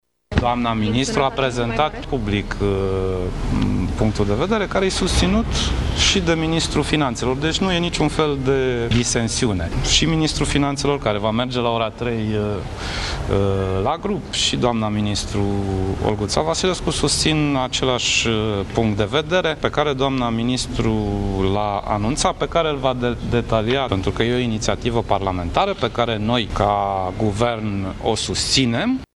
Premierul Sorin Grindeanu afirmă că, în ciuda speculațiilor, nu există disensiuni în Guvern pe tema salarizării bugetarilor: